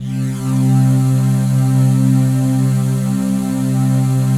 DM PAD2-62.wav